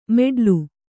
♪ meḍlu